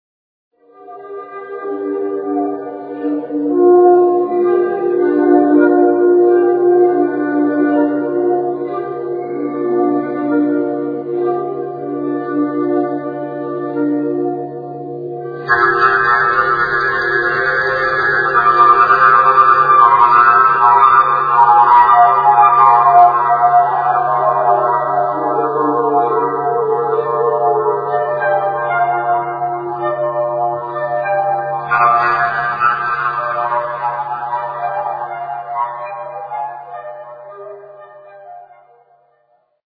For those, who are not "in the picture" - Fujara is Slovak traditional wood instrument (a thing looking like a "big pipe"), with characteristic, unreplaceable sound.
fujara
flute) - live from National Muzeum of Prague